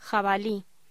Locución: Jabalí